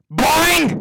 boing.wav